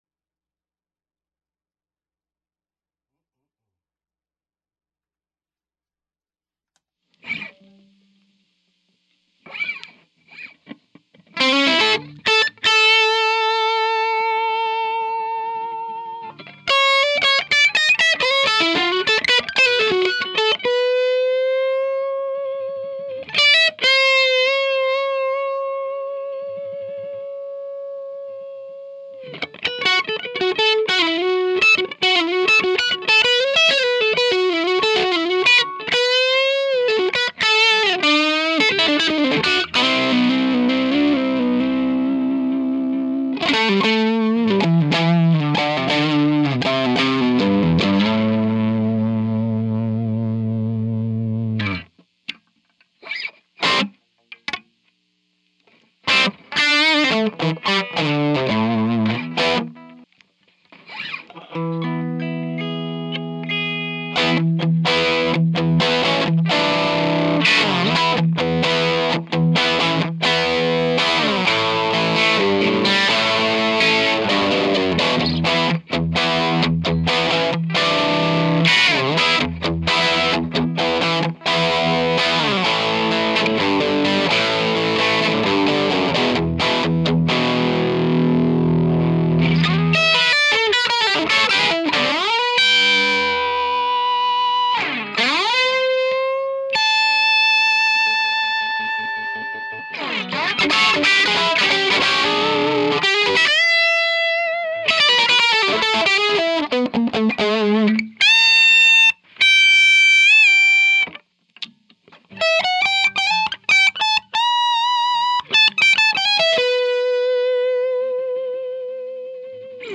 Here is the 6V6 side of the amp, boost on, with a les paul custom.